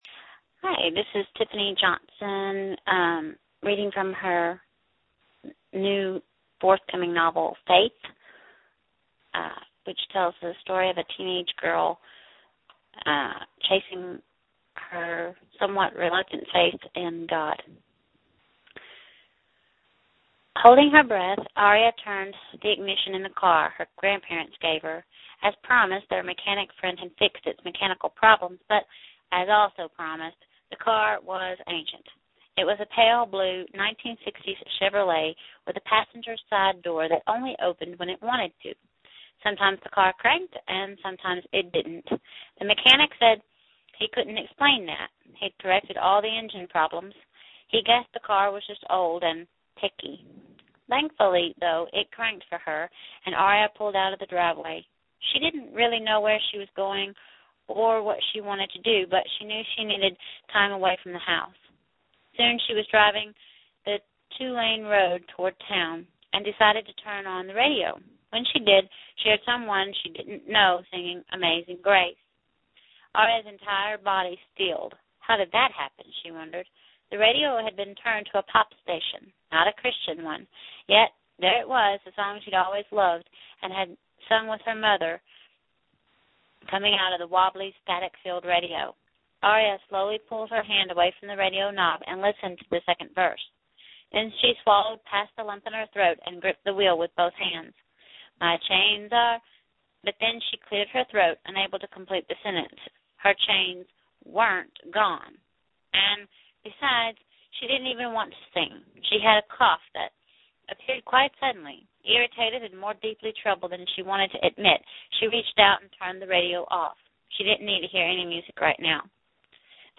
Reading of Faith